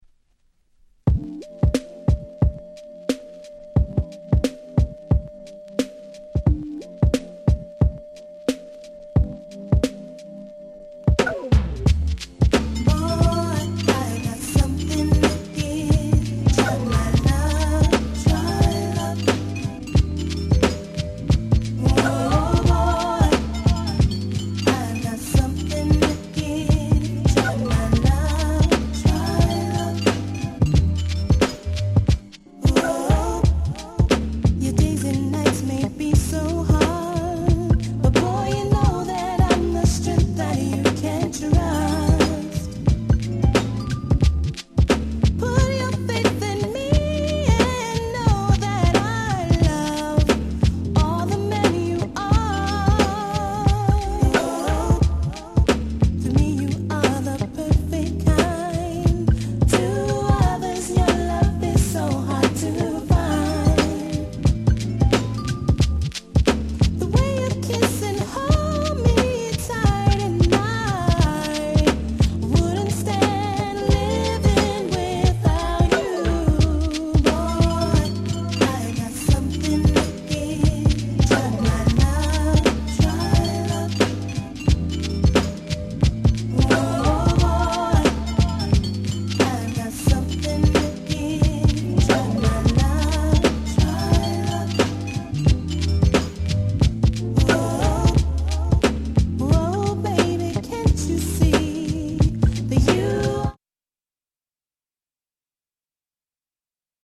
Nice Hip Hop Soul !!
ヒップホップソウル 90's R&B